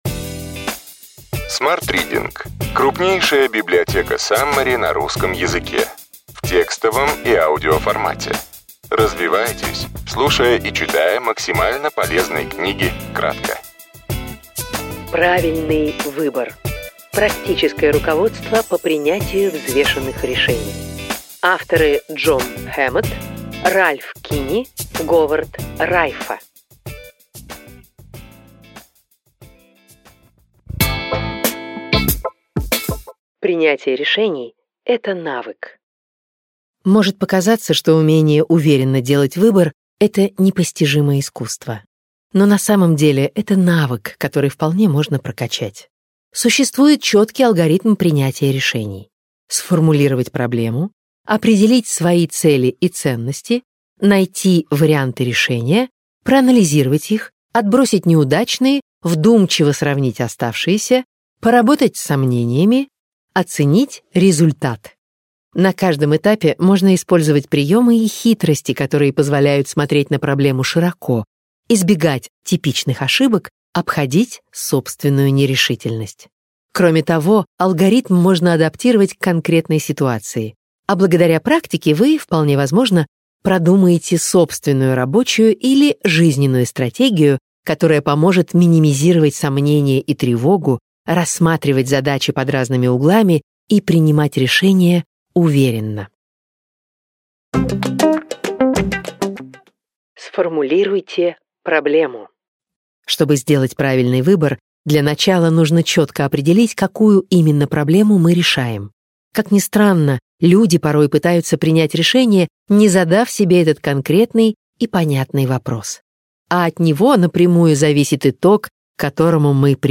Аудиокнига Правильный выбор. Практическое руководство по принятию взвешенных решений.